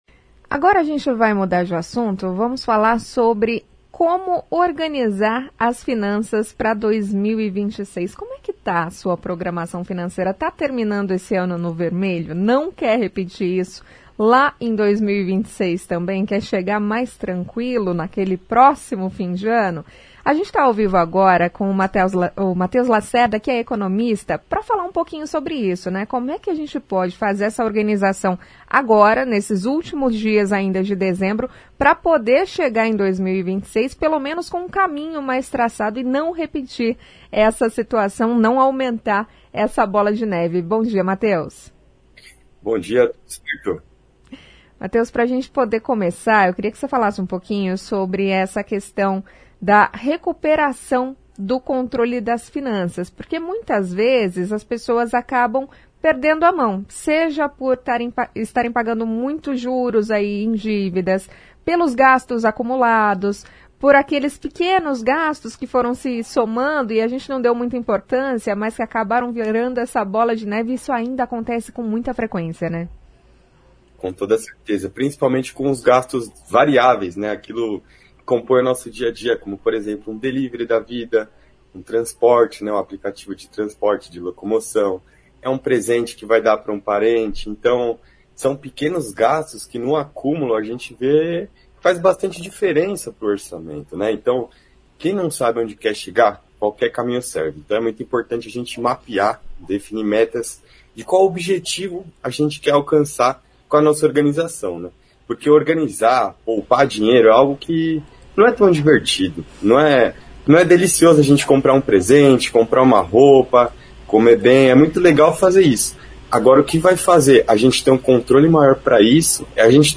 Para quem segue endividado o primeiro passo não é pagar dívidas, mas sim entender a própria situação, diz economista.